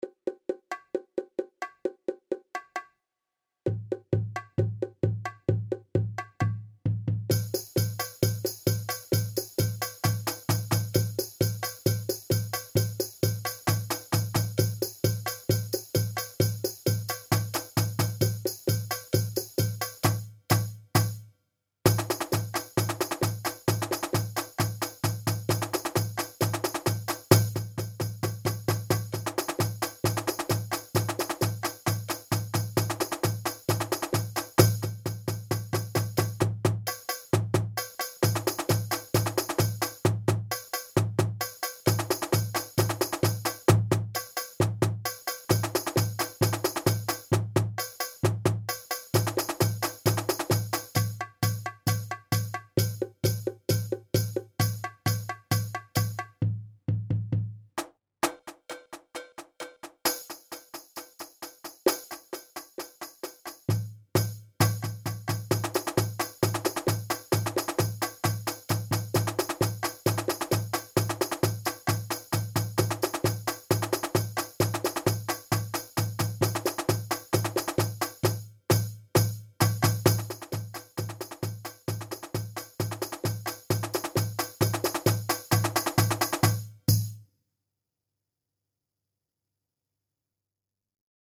Jeugd Ensemble